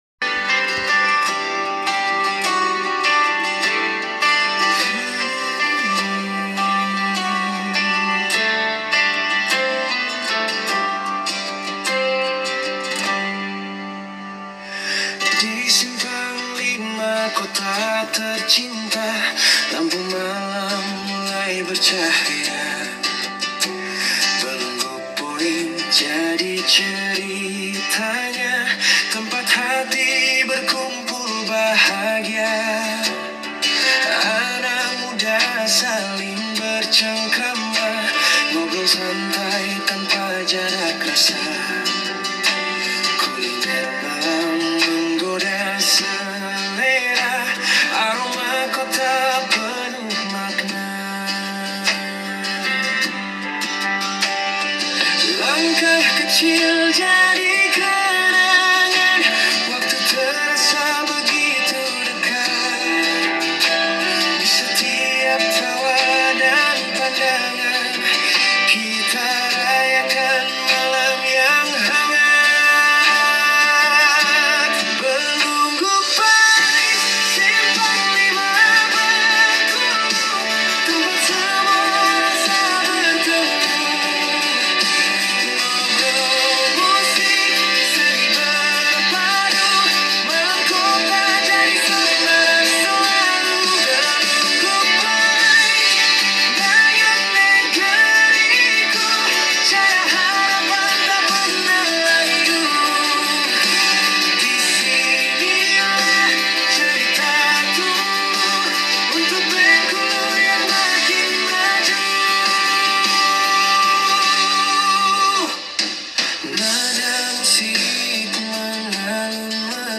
Untuk aransemen musik dan vokal, ia memanfaatkan aplikasi berbasis kecerdasan buatan (AI).